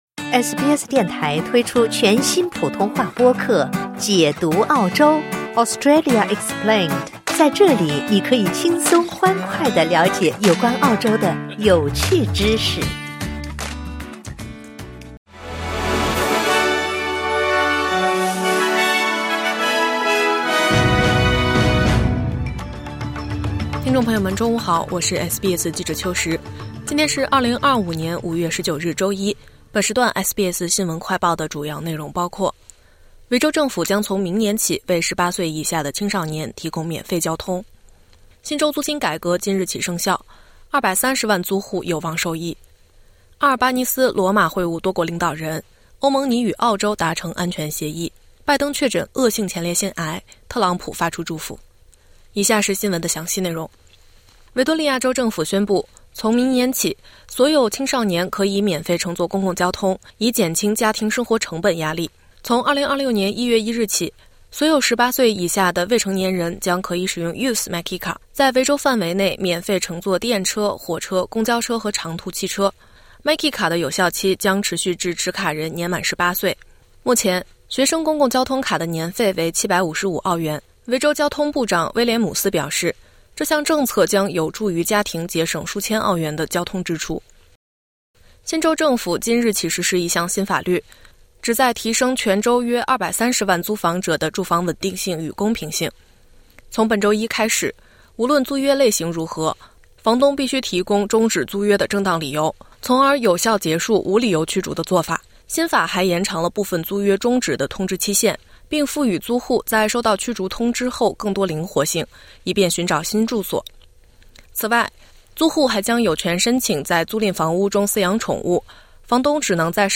【SBS新闻快报】维州政府将从明年起为未成年提供免费交通